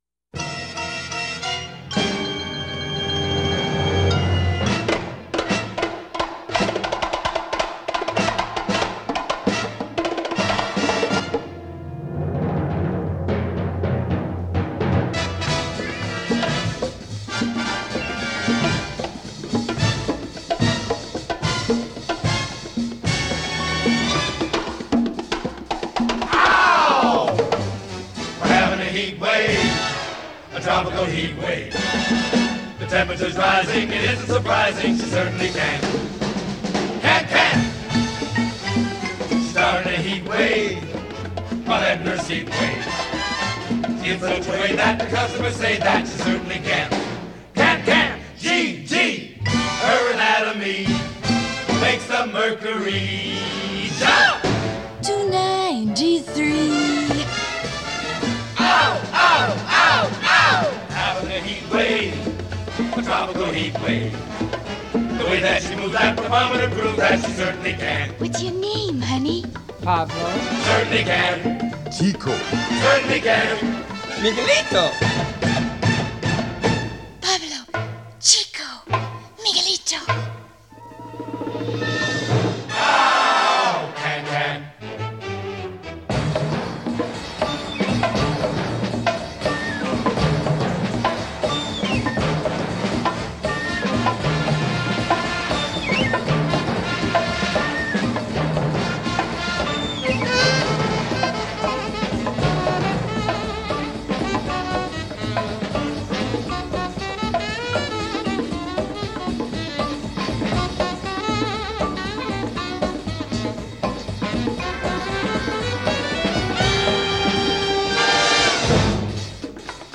1933 Genre: Musical   Artist